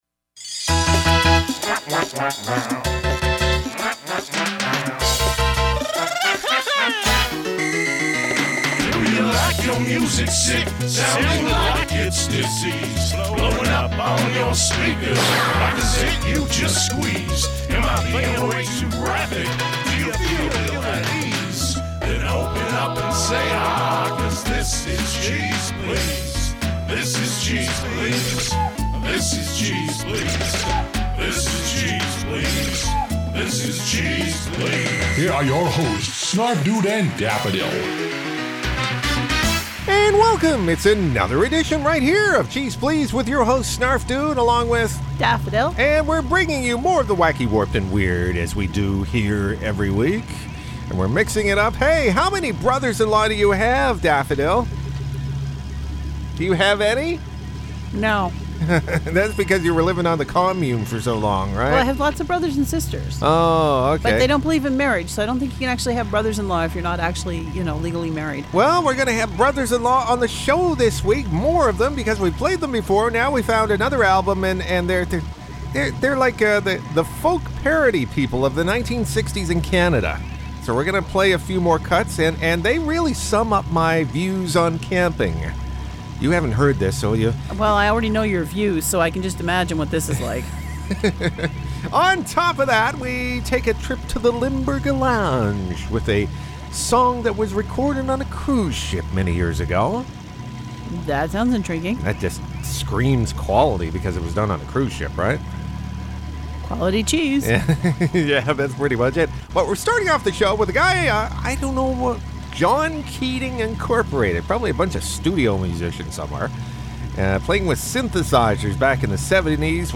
The Brothers In Law return with more folky parody foolishness and imply....